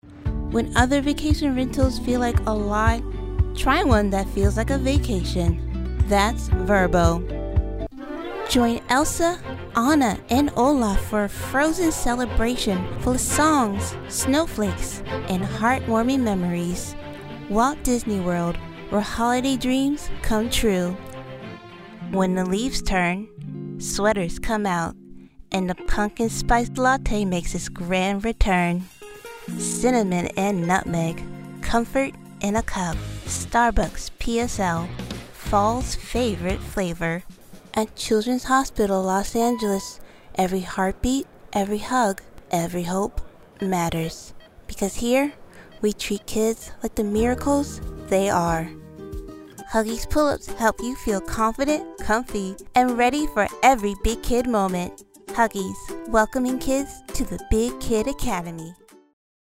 Commercial Demo
English - Southern U.S. English